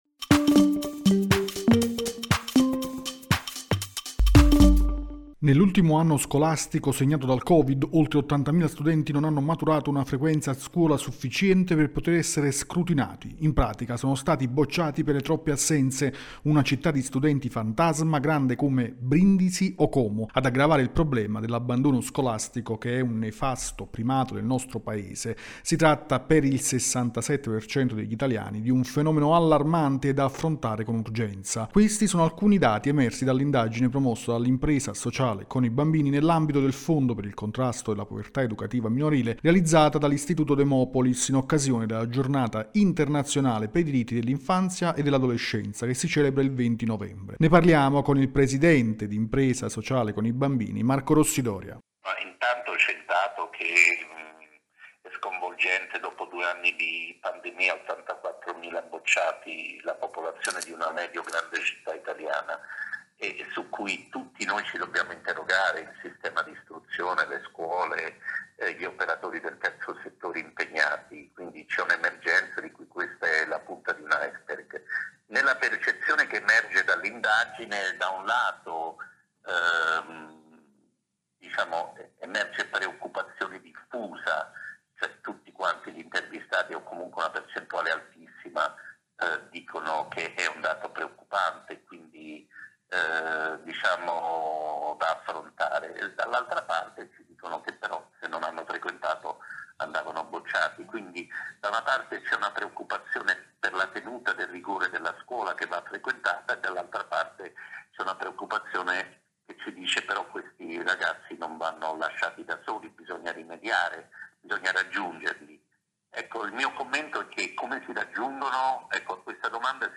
Intervista al presidente di Con i bambini Marco Rossi Doria